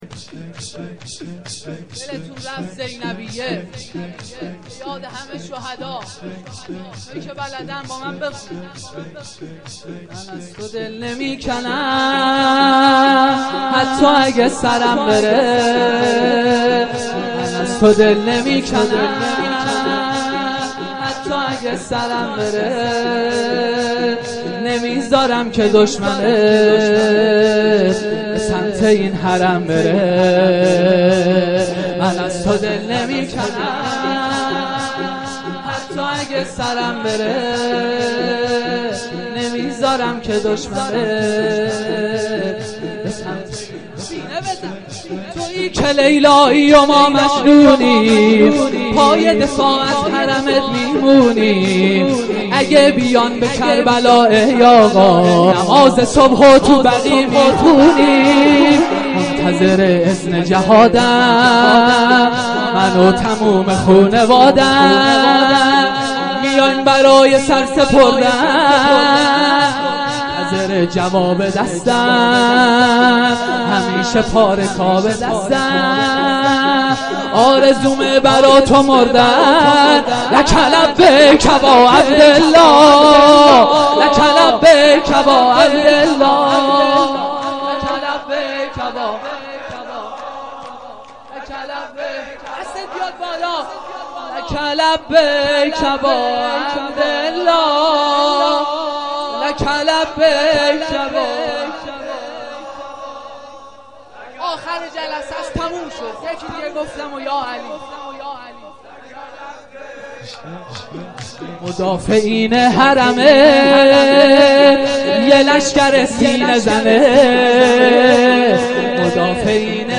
شب سوم مراسم عزای مادر سادات حضرت فاطمه زهرا (س)-فاطمیه اول